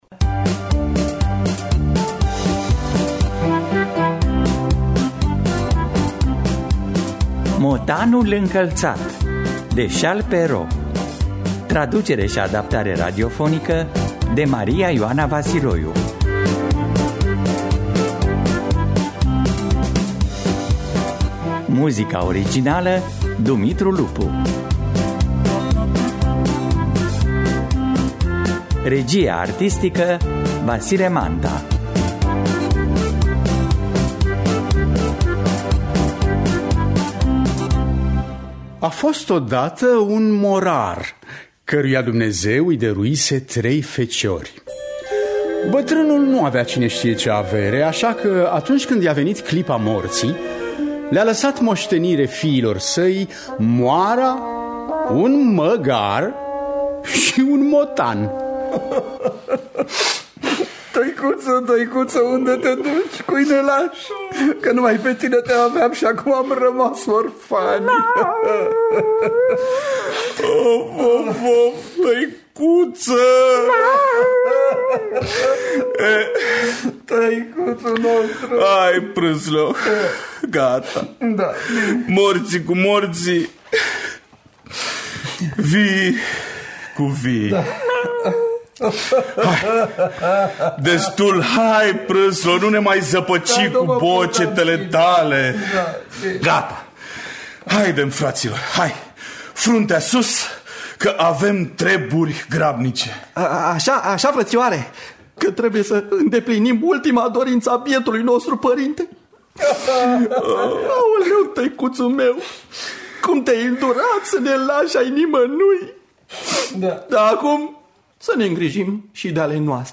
Motanul încălţat de Charles Perrault – Teatru Radiofonic Online